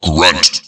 Grunt.wav